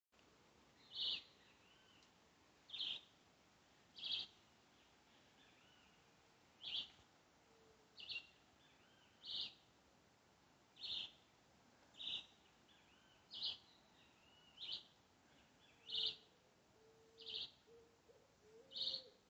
Chivi Vireo (Vireo chivi)
Life Stage: Adult
Location or protected area: Reserva Natural El Destino
Condition: Wild
Certainty: Recorded vocal